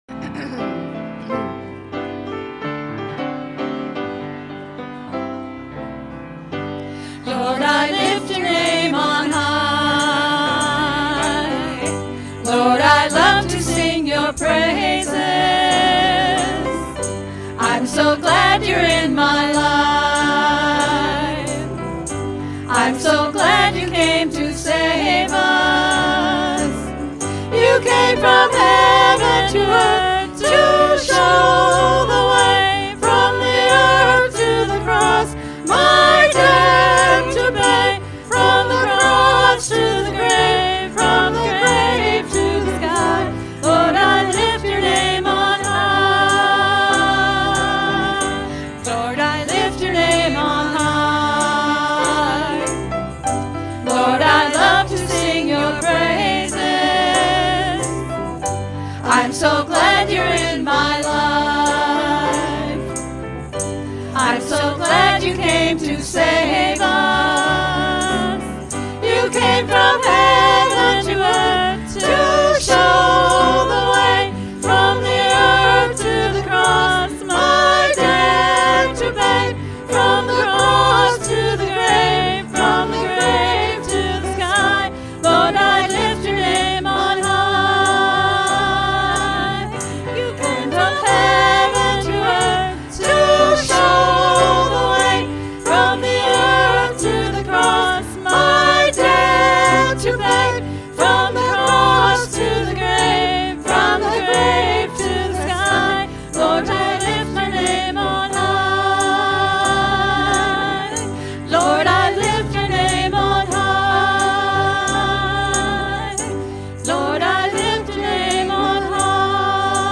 Worship-May-5-2024-Voice-Only.mp3